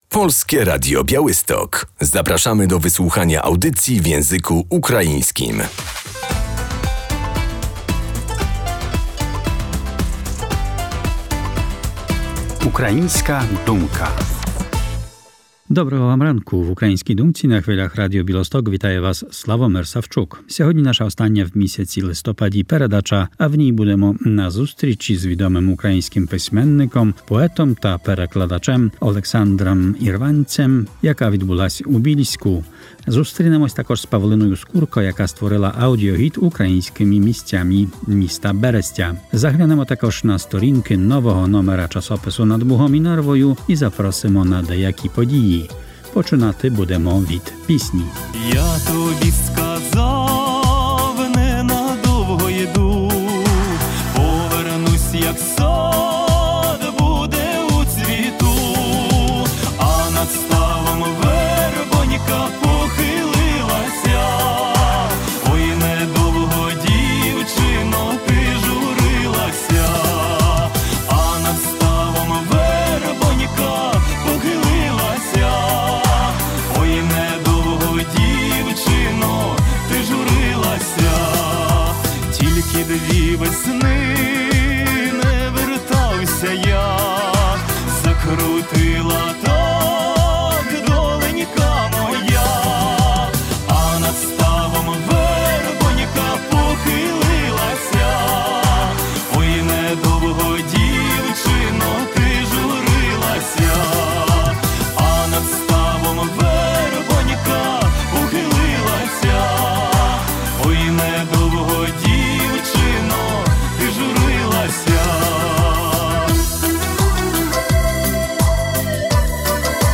W Bielsku Podlaskim odbyło się spotkanie z pisarzem ukraińskim Ołeksandrem Irwancem.